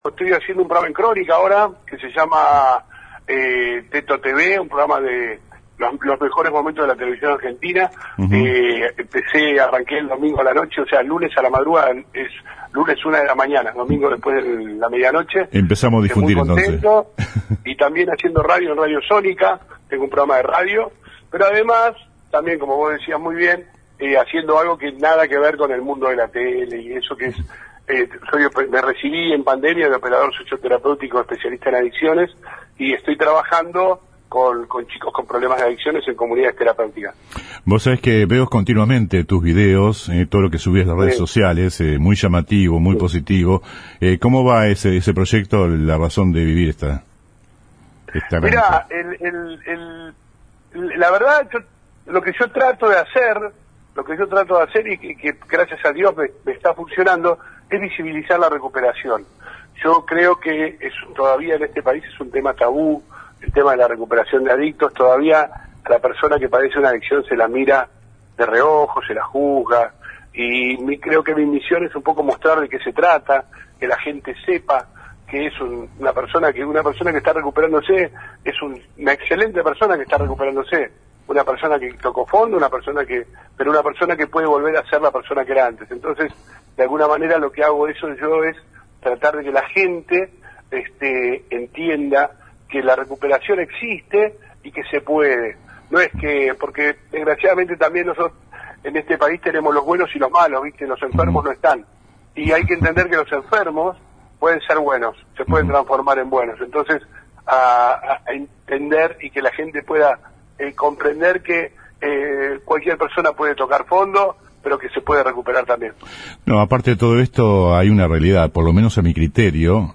El animador y conductor televisivo -ex integrante de la troupe de “Videomatch” en la década del ’90, habló hoy por la mañana